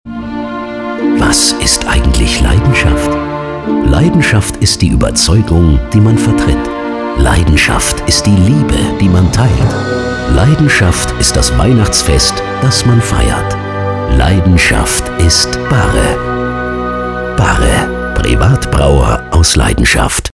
Die Funkspots werden jeweils mit der Frage „Was ist eigentlich…“ eröffnet und um den jeweiligen Markenwert ergänzt. Ein Sprecher mit tiefer, emotionaler Stimme beantwortet diese Frage dann im Sinne von Barre. Die Markenwerte „Heimat“, „Tradition“ und „Leidenschaft“ wurden zur Weihnachtszeit um „Weihnachten“ erweitert und entsprechend festliche Musik untermalt die emotionalen Funkspots sowie den Reminder.